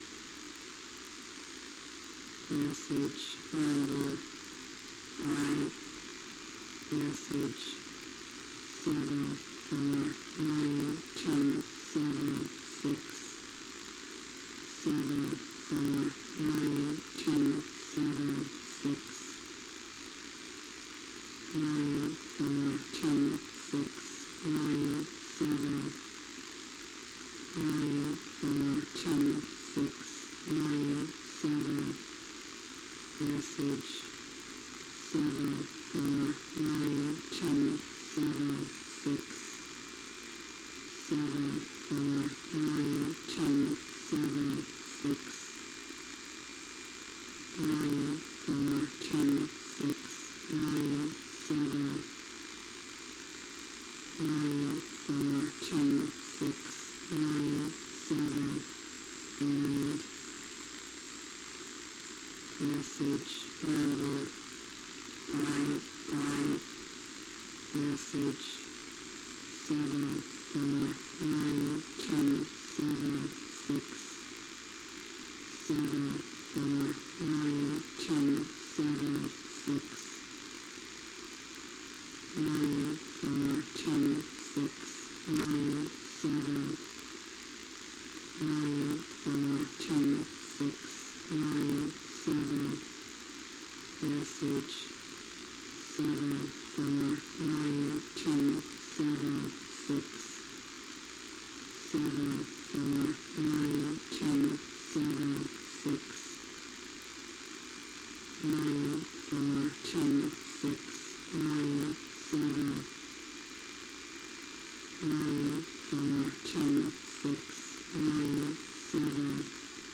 > At 1315UTC I monitored an odd number station.
> Frequency: 10810khz